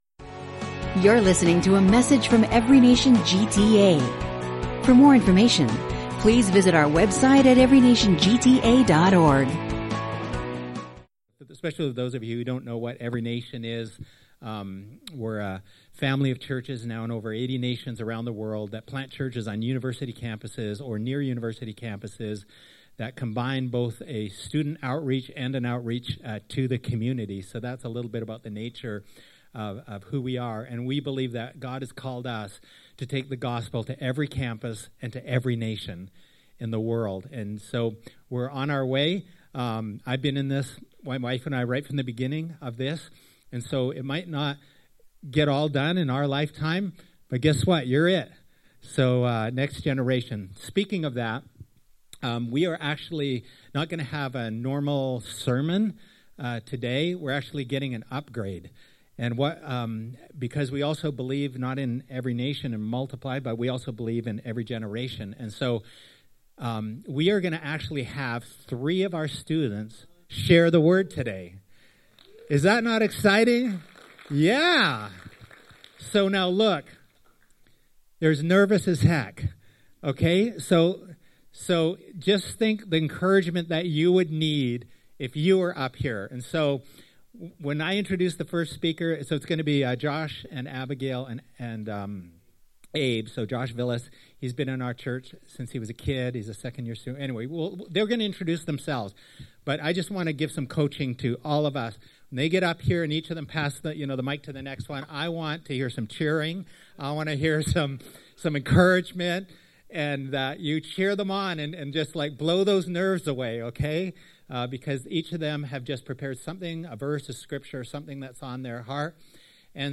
In this special service, we hear from three U of T students about what God has been stirring in their hearts: seeking Godly wisdom, overcoming fear, and trusting in the midst of trials.